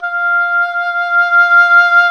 WND OBOE2 0A.wav